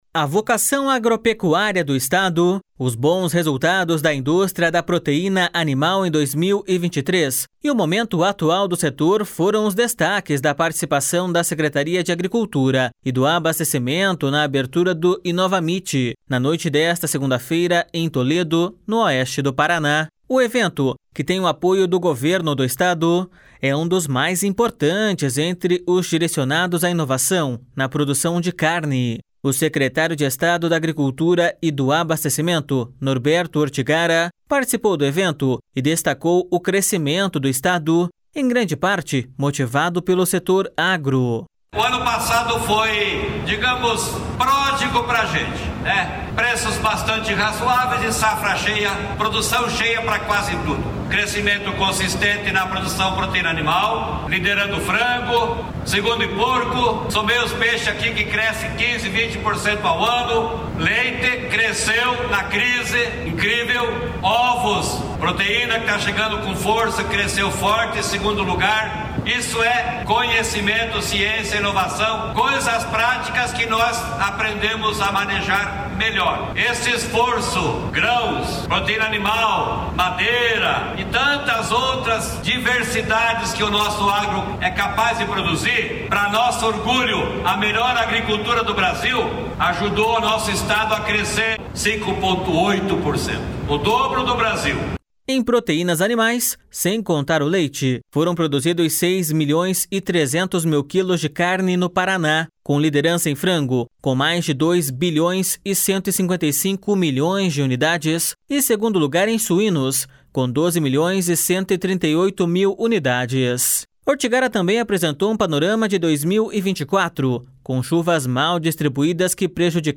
O secretário de Estado da Agricultura e do Abastecimento, Norberto Ortigara, participou do evento e destacou o crescimento do Estado, em grande parte motivado pelo setor agro.// SONORA NORBERTO ORTIGARA.//